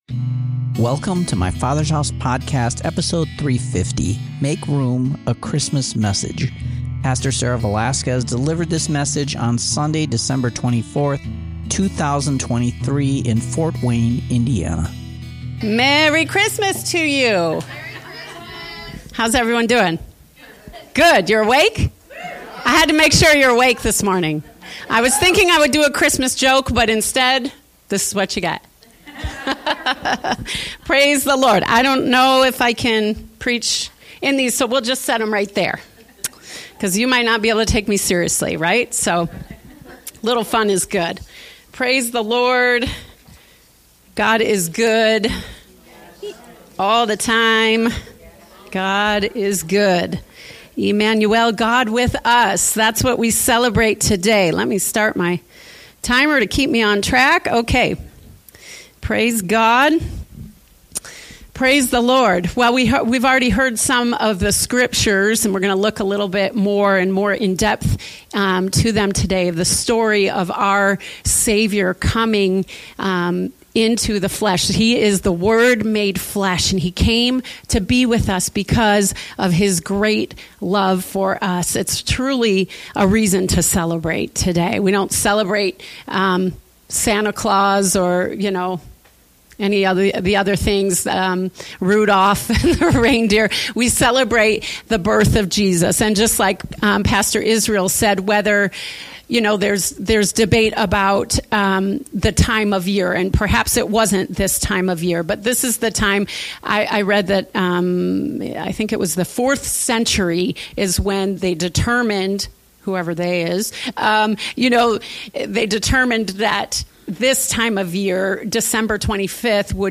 Make Room, A Christmas Message